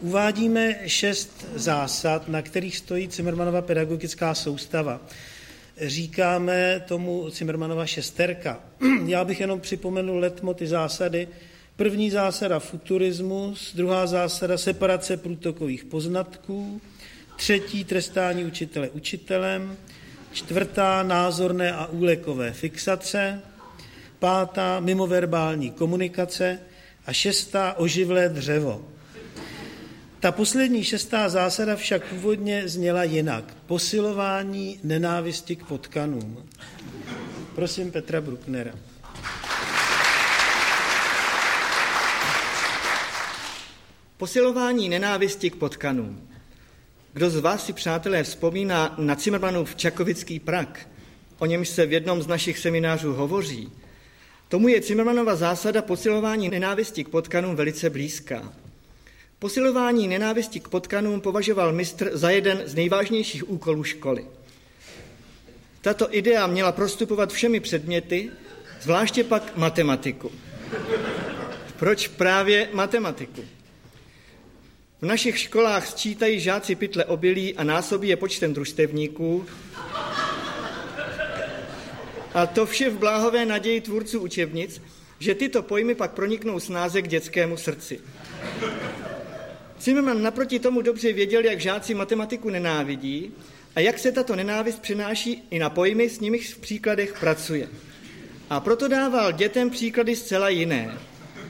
Audio kniha
Ukázka z knihy
Všechno, co zahrnuje, bylo původně součástí jednotlivých her, avšak z nejrůznějších důvodů /většinou časových/ se to do inscenací jaksi nevešlo. Scénky, básně a písně seřazené na tomto albu jsou tedy historickou mozaikou cimrmanovské inspirace a v osobité interpretaci členů souboru doslova ohňostrojem jejich neopakovatelného humoru, na který mají už více než tři desetiletí patent.